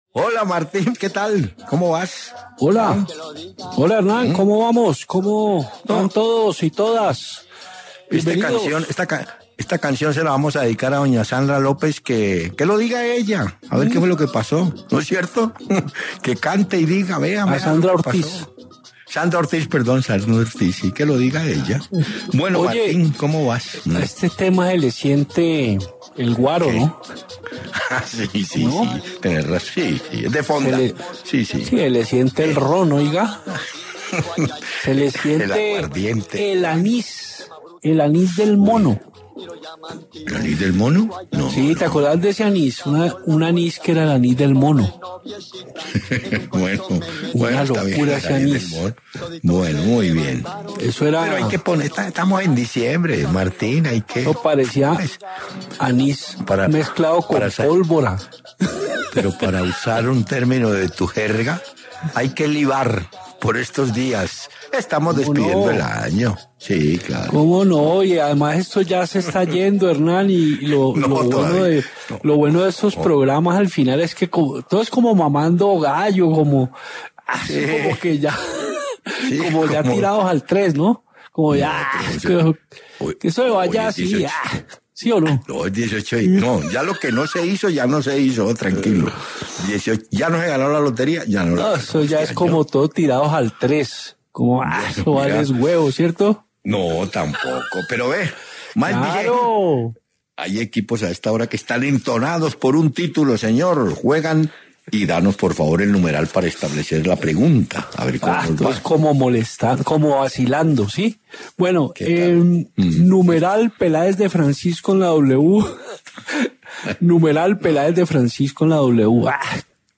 Hernán Peláez y Martín de Francisco dialogaron sobre la final del FPC entre Nacional y Tolima.